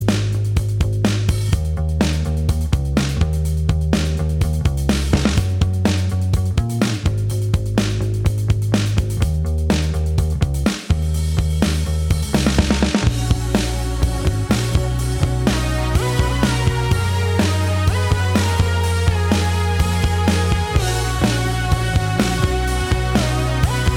No Guitars Pop